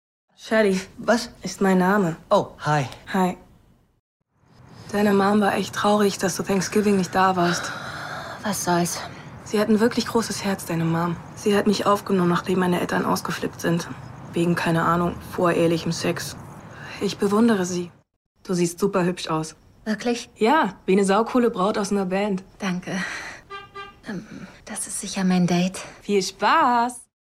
dunkel, sonor, souverän, sehr variabel, markant
Jung (18-30)
Synchron - Lady Bird / Rolle: Shelly
Lip-Sync (Synchron)